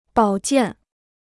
保健 (bǎo jiàn) พจนานุกรมจีนฟรี